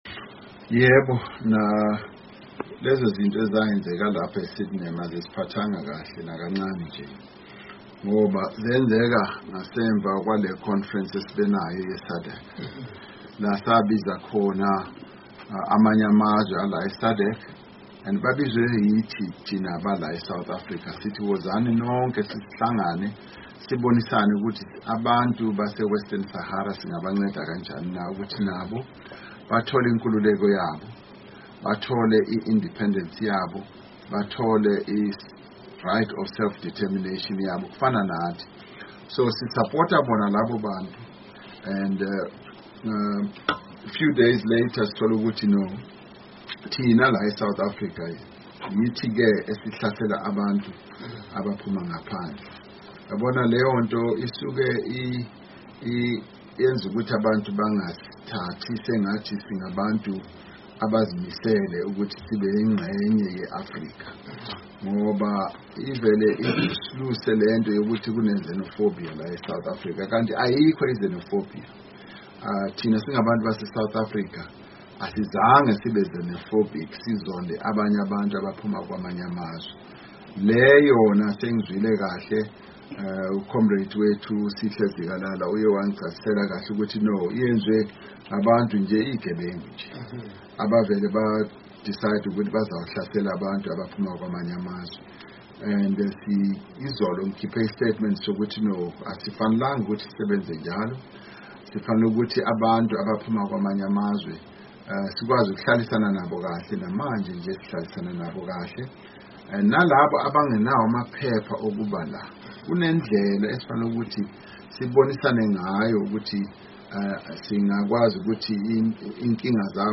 Asizweni umongameli Ramaphosa exoxa ngaloludaba lomsakazo weUkhozi FM oweSouth Africa.